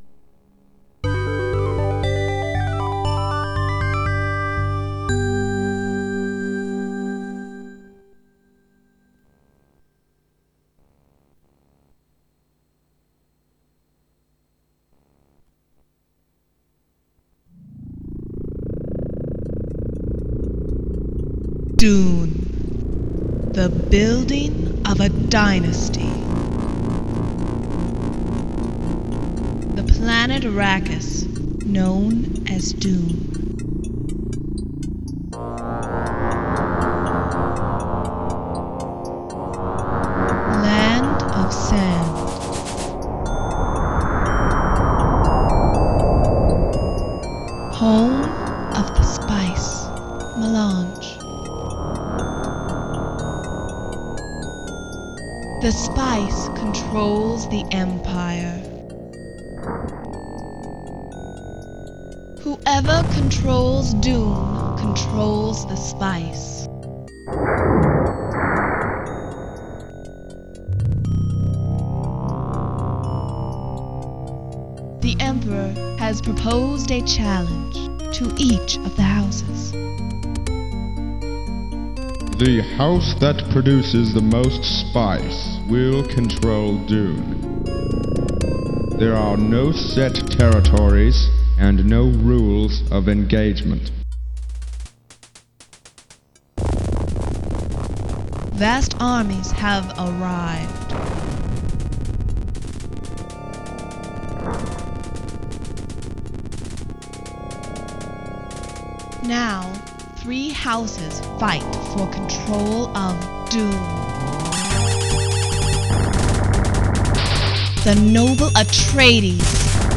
486DX-33, Sound Blaster Pro
Weighted SnR (Speaker Out): -68 dB
(Speaker out on left, Line Out on right)